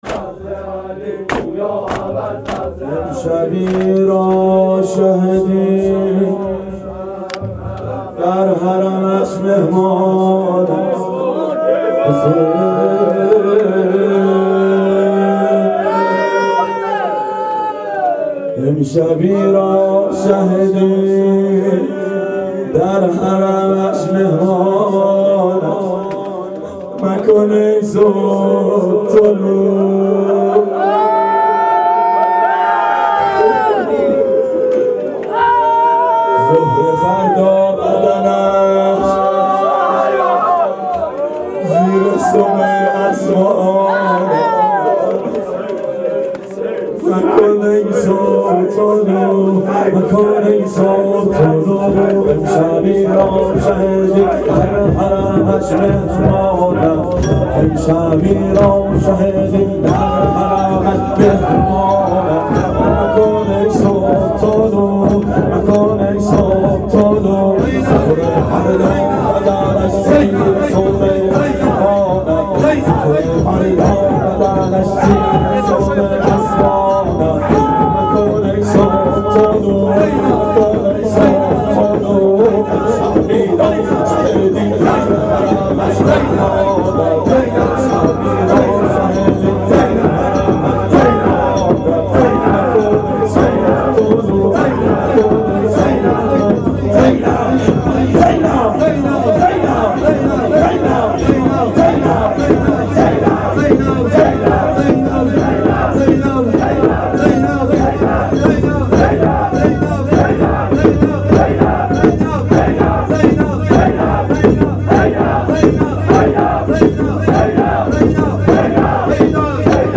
شب عاشورا 92 هیأت عاشقان اباالفضل علیه السلام منارجنبان